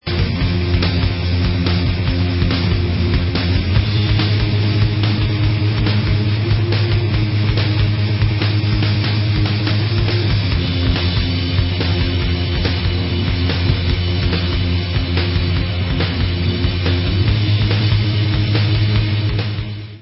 Rock/Alternative Metal